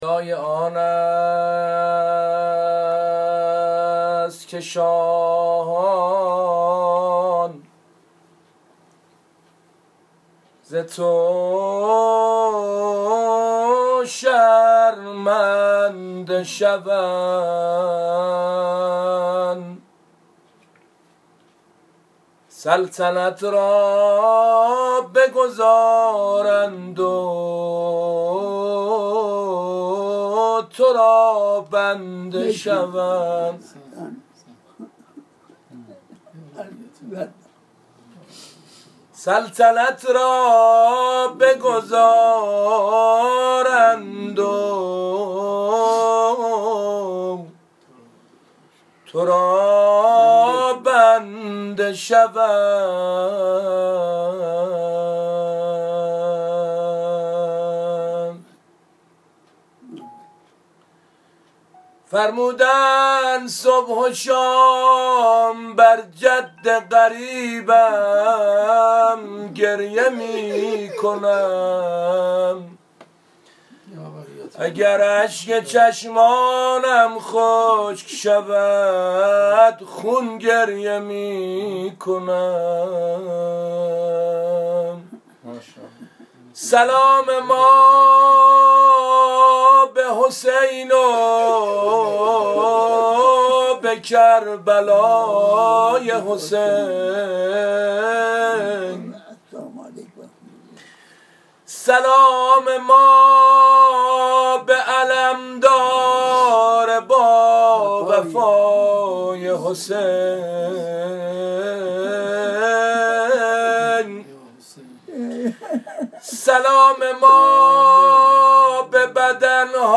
مداحی
در حضور آیت الله صافی گلپایگانی